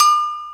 Perc (12).wav